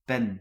Ääntäminen
IPA: /plym/